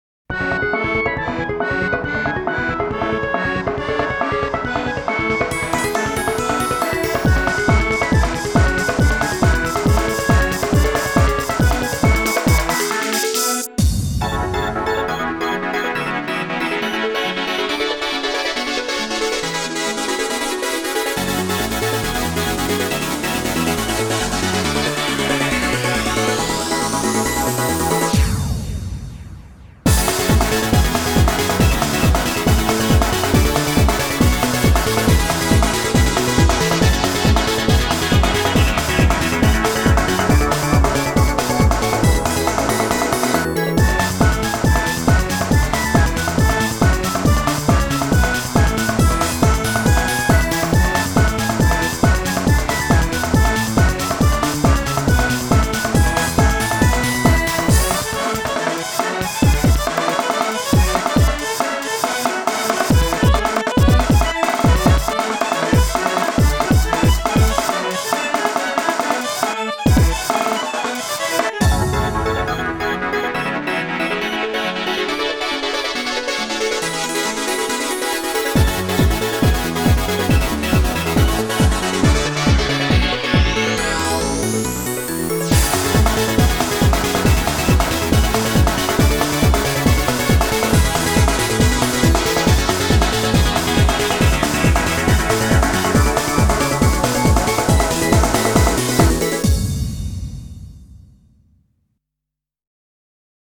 BPM69-138
Audio QualityPerfect (High Quality)
Genre: CELTRANCE.